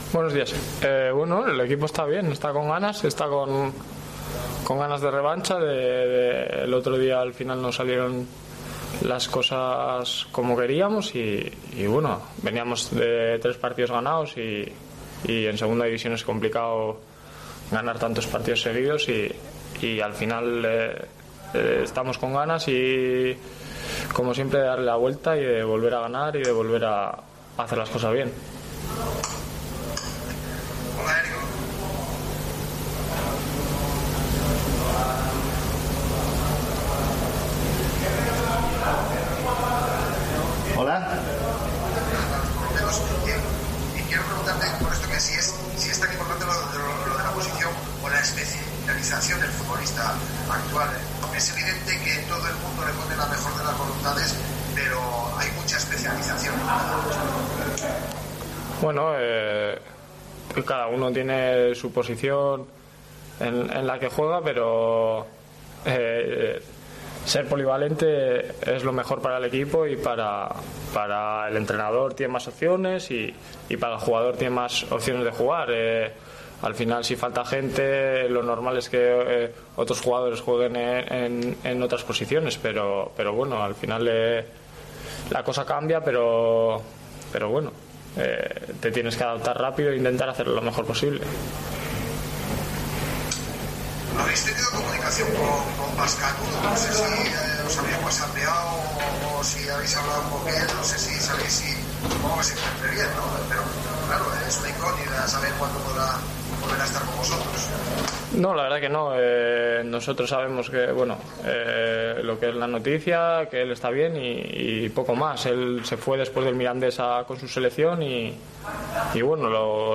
AUDIO: Escucha aquí las palabras del centrocampista de la Deportiva Ponferradina